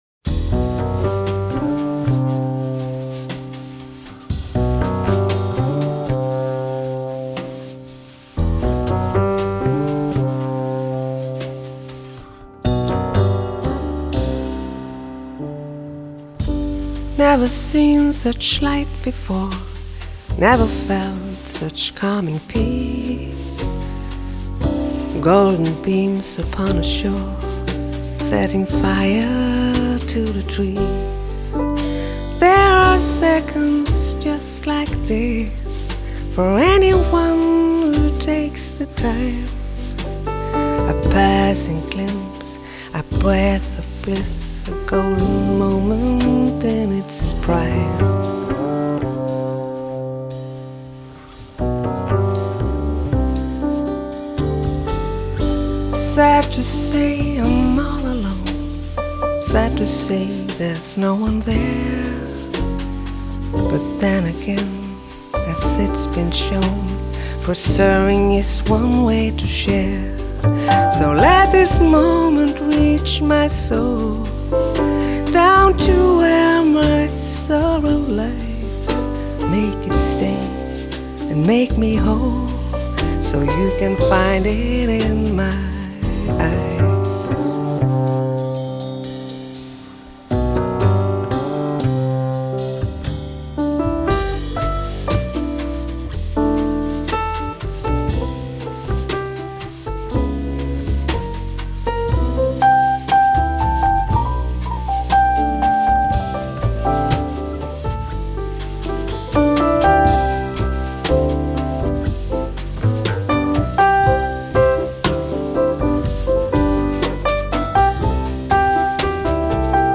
正是她朴实的风格吸引了我！